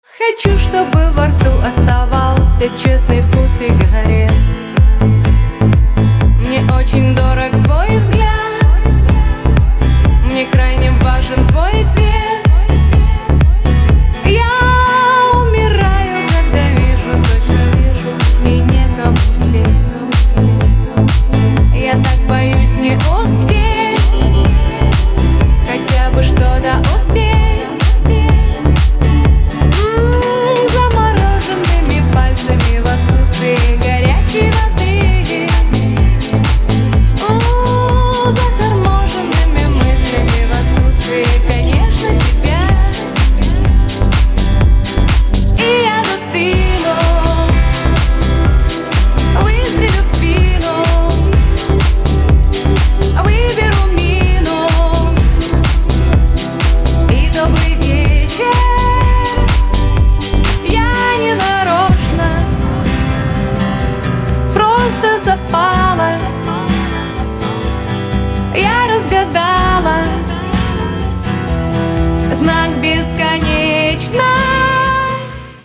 • Качество: 192, Stereo
гитара
красивые
женский вокал
deep house
dance
спокойные